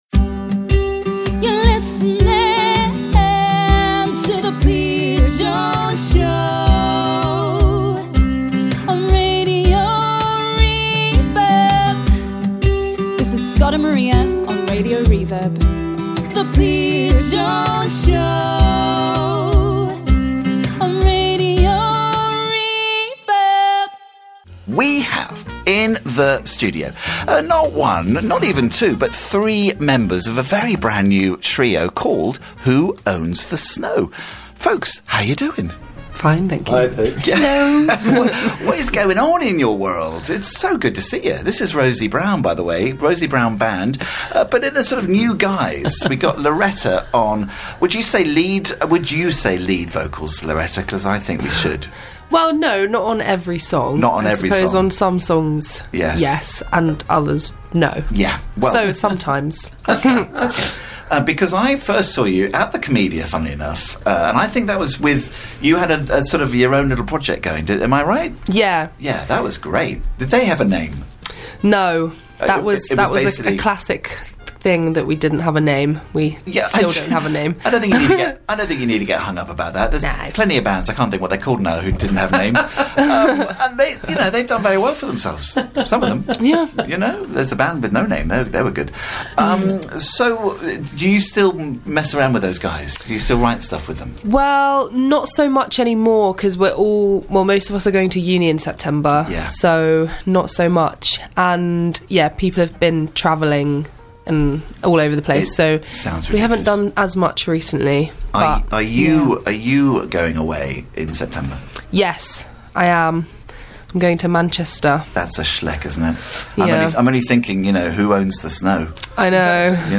Live session with Who Owns The Snow
Wonderful stuff and stunning harmonies.